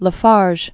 (lə färzh, färj), John 1835-1910.